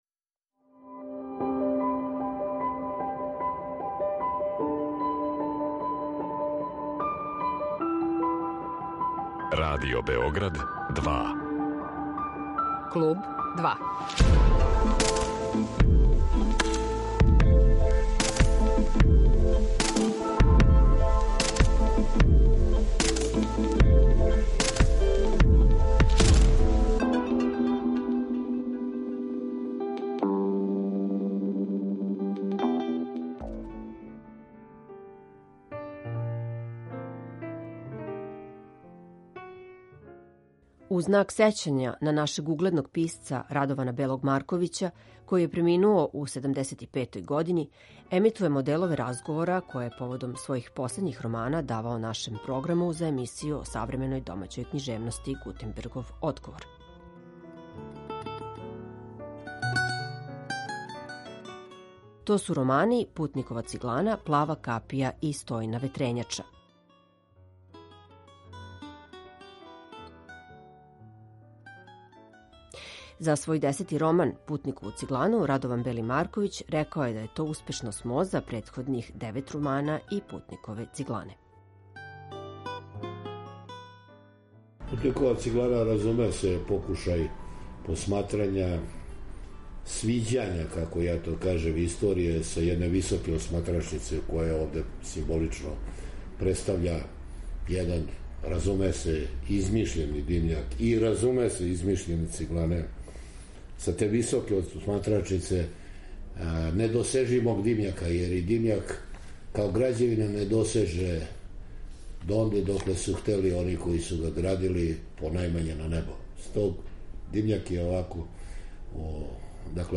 У знак сећања на нашег угледног писца Радована Белог Марковића, који је преминуо у 75. години, емитујемо делове разговора које је поводом својих последњих романа давао нашем програму.